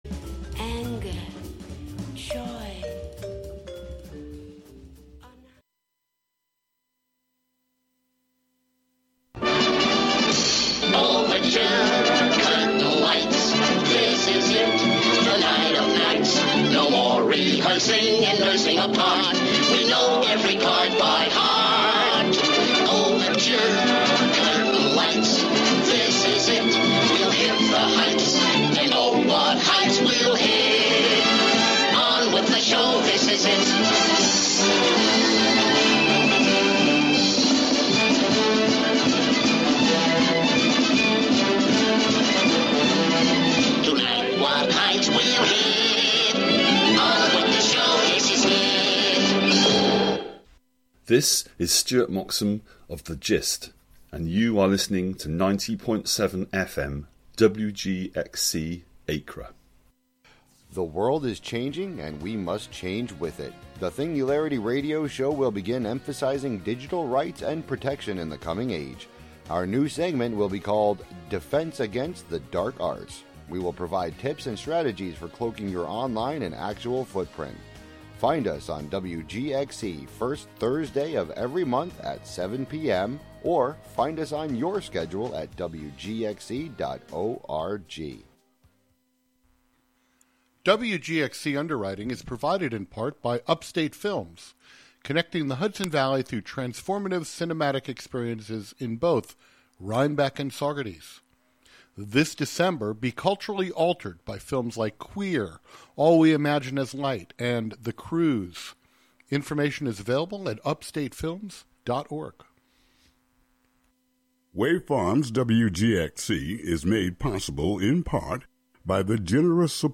instrumental album